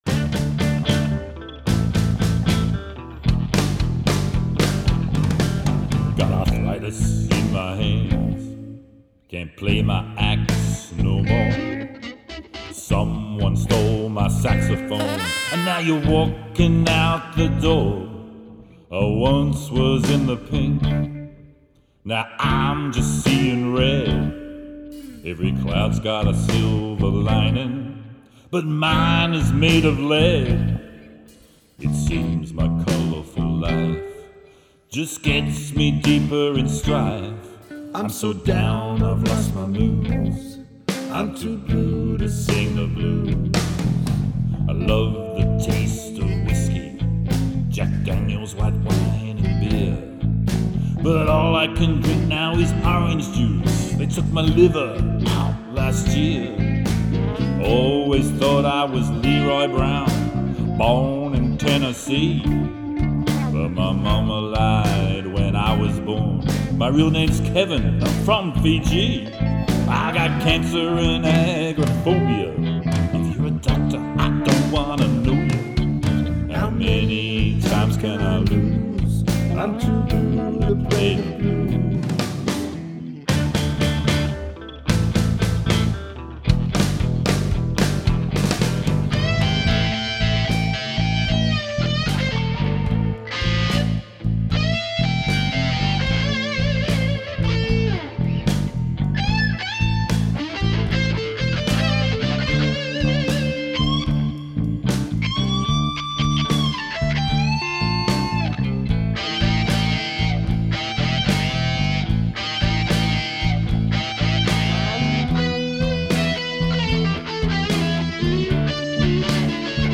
Guitar
Vocals, Guitar & Drums
Vocals & Bass
Saxophone
Lead Guitar
and the vocals were recorded using an AKG-C414 microphone.